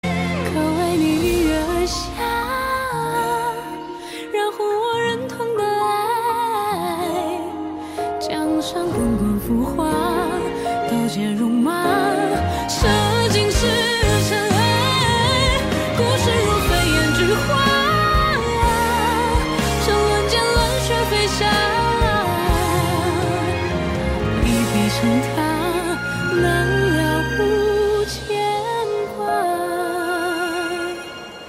Nhạc Chuông Nhạc Hoa